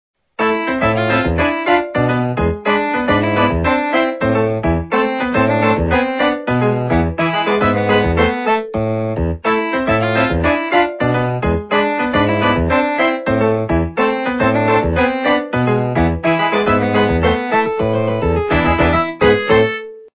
западная эстрада
полифоническую мелодию